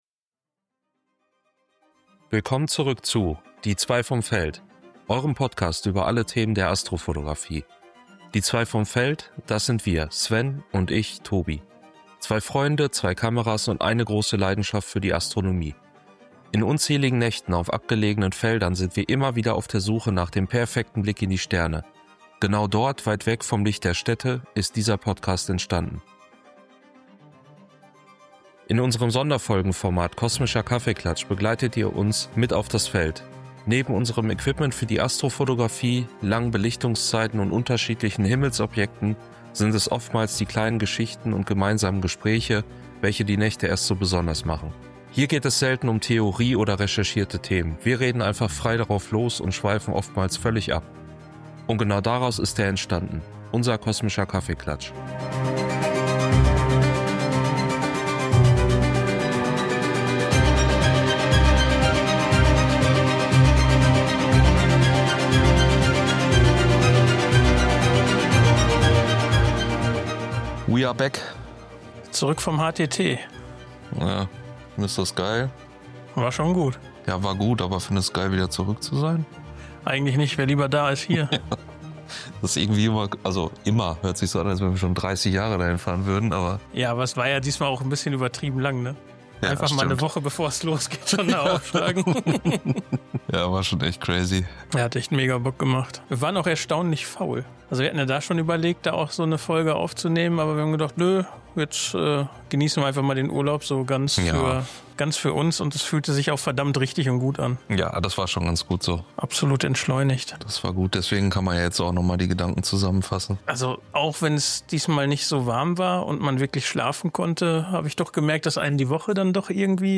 Beschreibung vor 6 Monaten In unserem Sonderfolgenformat Kosmischer Kaffeeklatsch begleitet ihr uns mit auf das Feld.
Hier geht es selten um Theorie oder recherchierte Themen, wir reden einfach frei drauf los und schweifen oftmals völlig ab.